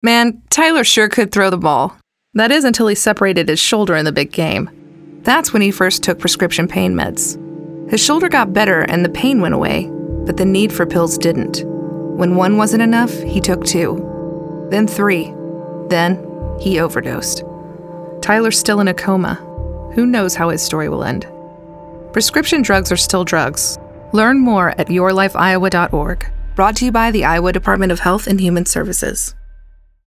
:30 Radio Spot | Pain Went Away